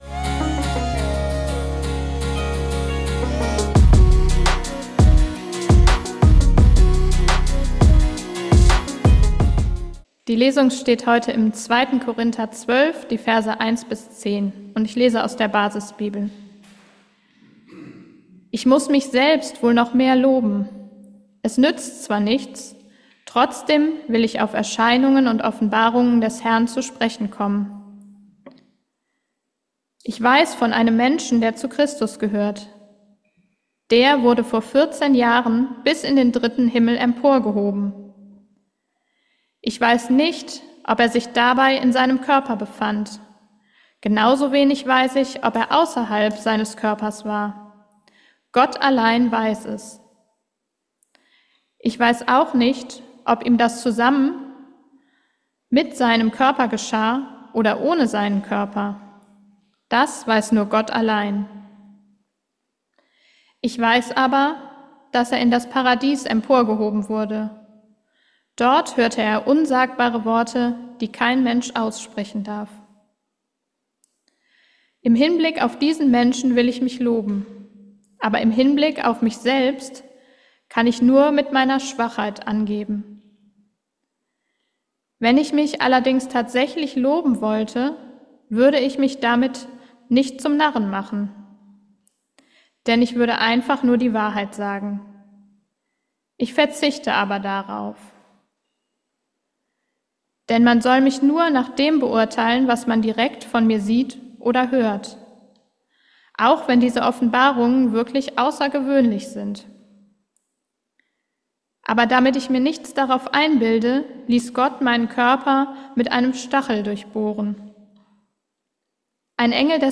Geistliches Leben in der Nachfolge Jesu und Leben in der Gesellschaft widersprechen sich geradezu. Eine Predigt aus der Freien evangelischen Gemeinde Wuppertal-Barmen am 14.7.2024. Die Wirklichkeit des christlichen Glaubens ist oft hart.